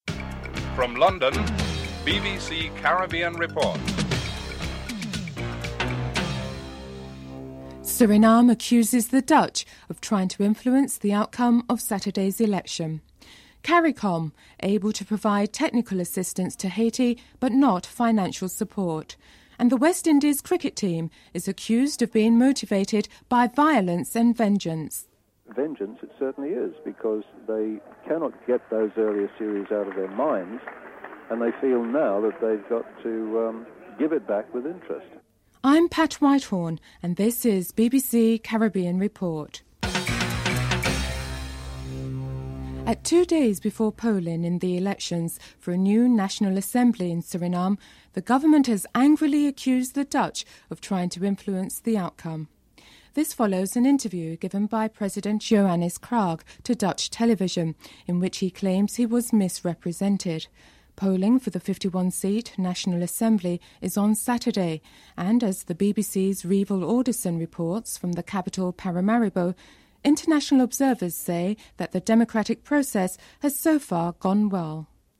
1. Headlines (00:00-00:41)
Comments from members of the public at the public meeting are featured (0042-02:51)